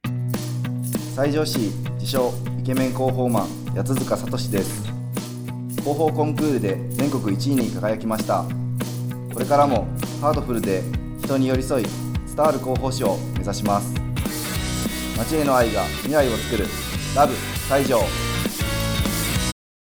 「ちゃんゆ胃の胃袋満たします」のラジオCM（20秒）を大公開中！